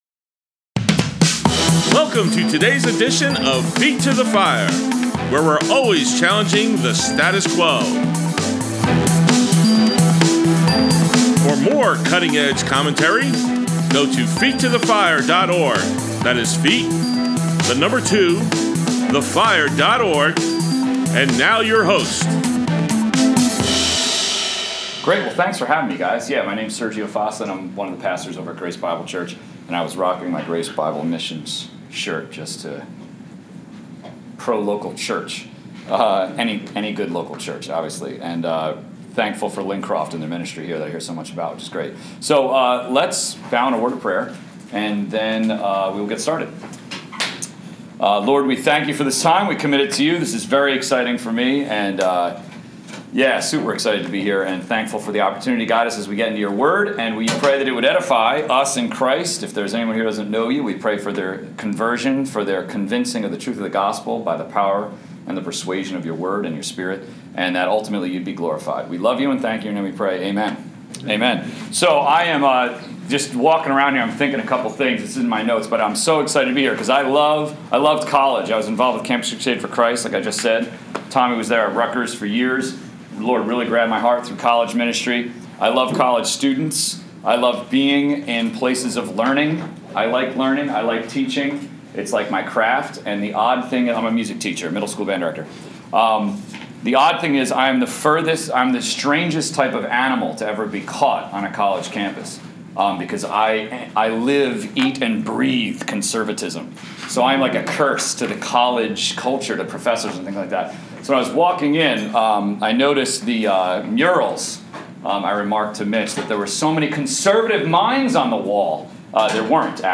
Brookdale Community College, NJ